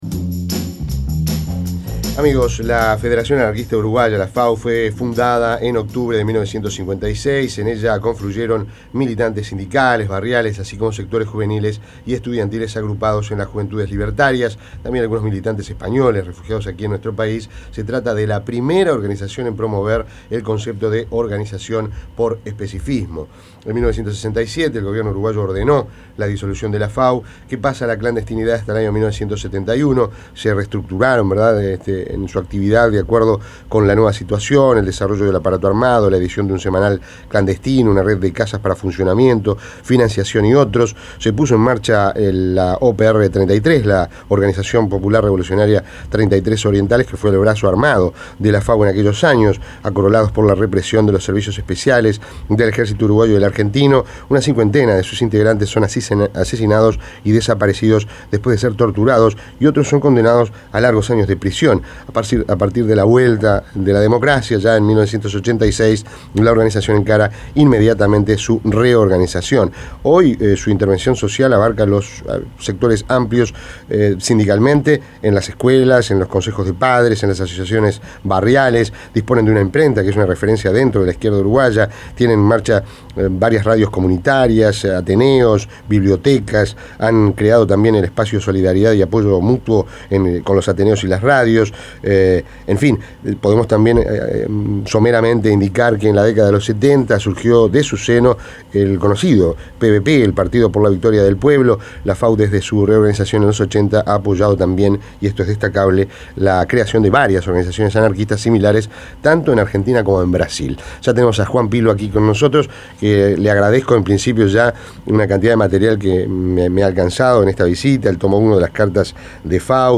Entrevista en Rompkbzas F.A.U.